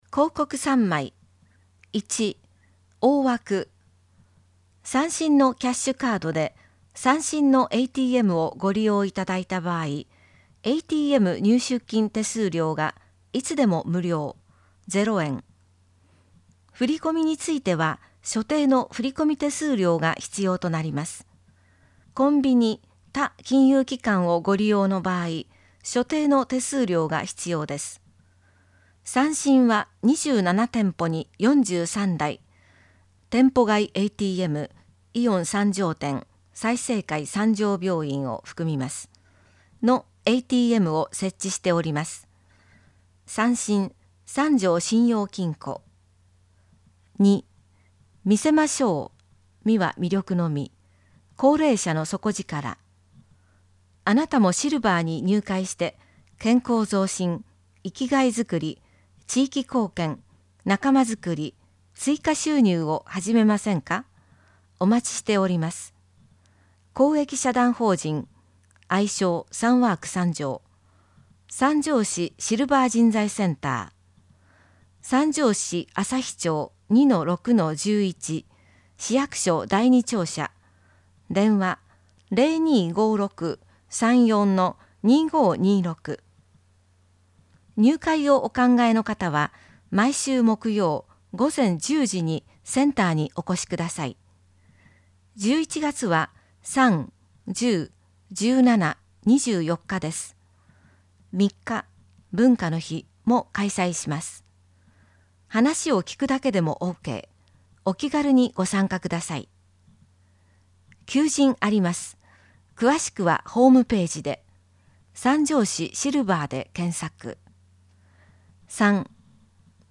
広報さんじょうを音声でお届けします。